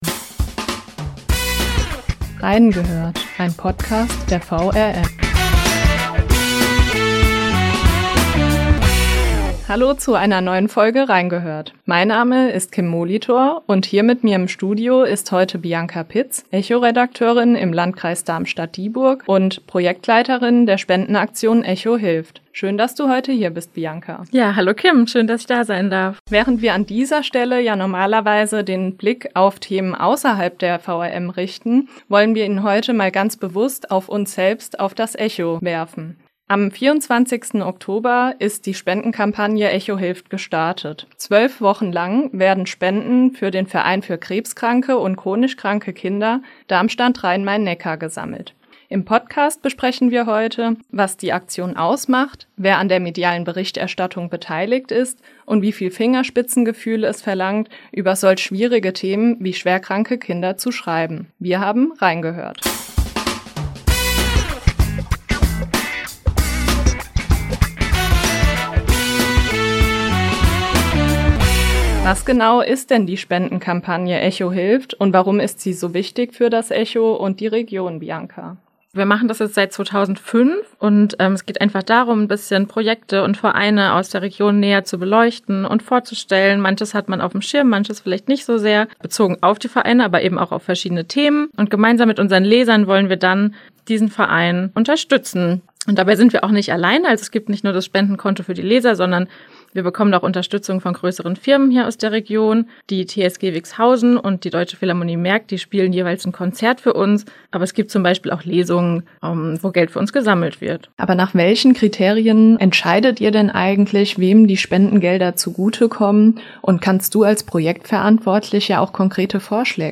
Im Podcast sprechen sie darüber, warum das Engagement so wichtig für die Region ist, wie es gelingt, über emotional bewegende Schicksale schwerkranker Kinder zu schreiben und wie es den Kollegen gelingt, diese Themen in den normalen Redaktionsalltag zu integrieren.